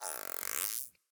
Minecraft Version Minecraft Version 25w18a Latest Release | Latest Snapshot 25w18a / assets / minecraft / sounds / mob / dolphin / idle_water3.ogg Compare With Compare With Latest Release | Latest Snapshot
idle_water3.ogg